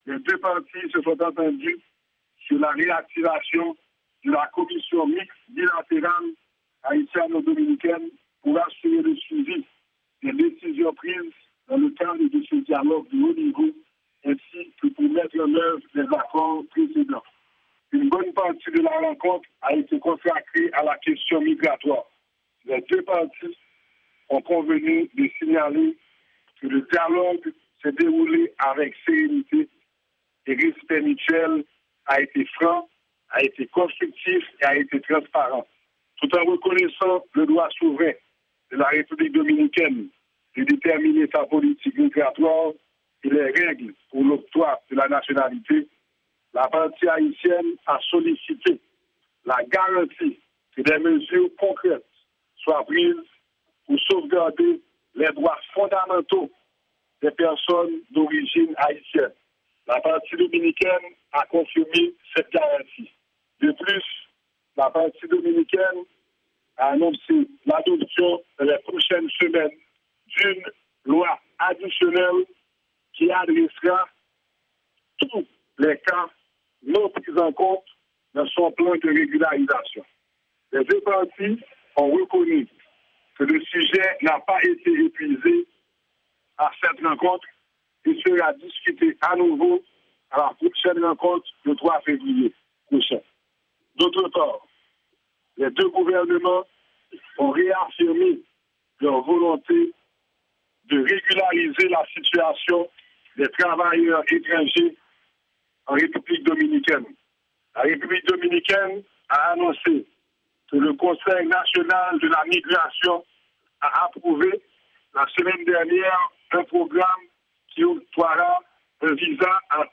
Deklarasyon-Wanament-Premye-Minis Laurent Lamothe